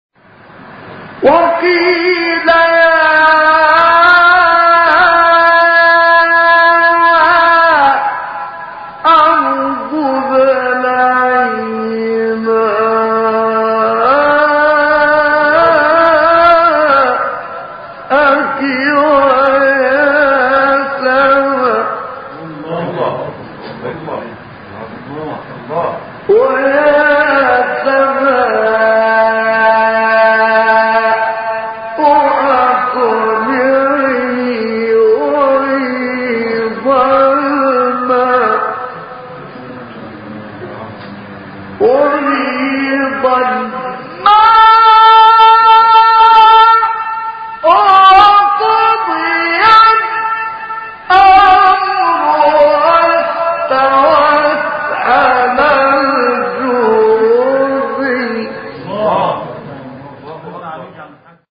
سوره : هود آیه : 44 استاد : محمد عبدالعزیز حصان مقام : رست قبلی بعدی